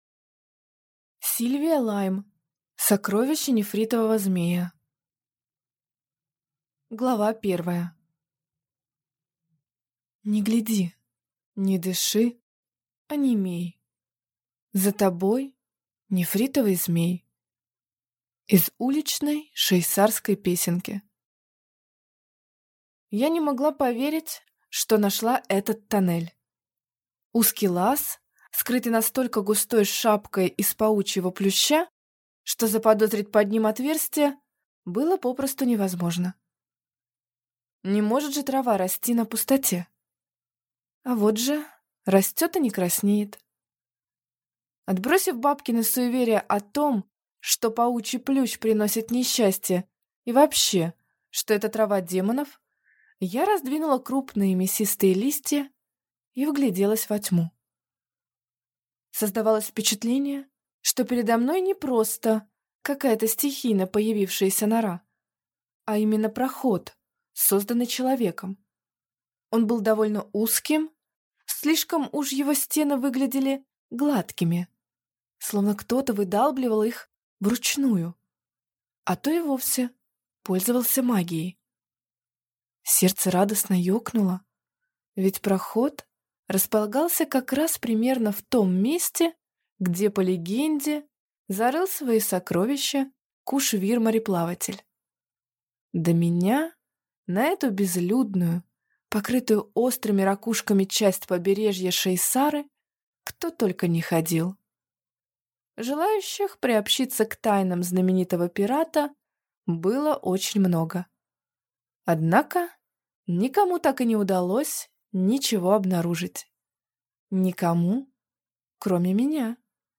Аудиокнига Сокровище Нефритового змея | Библиотека аудиокниг